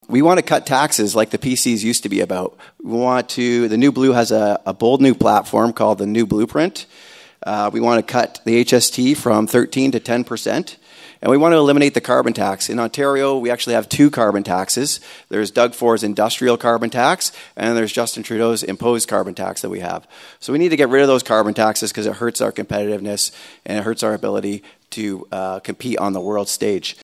The Haldimand-Norfolk candidates spoke on the topic at the Royal Canadian Legion in Simcoe on Thursday night.